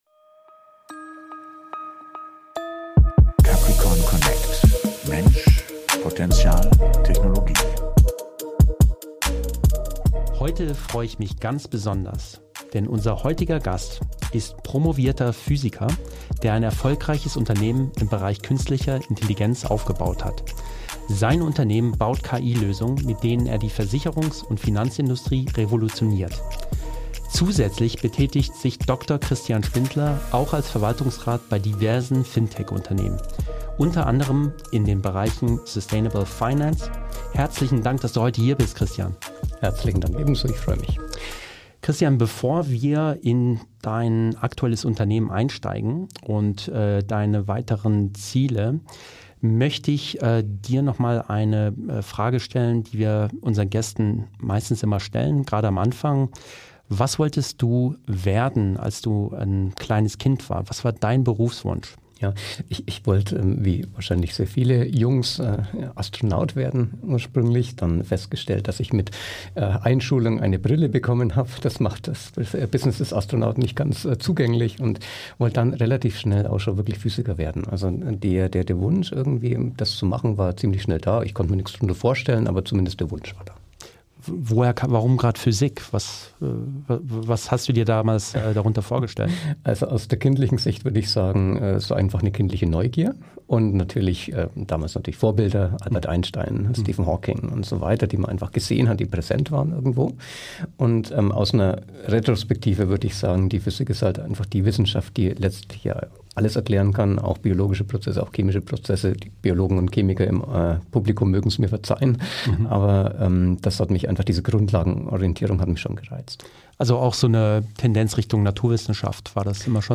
#8 - Interview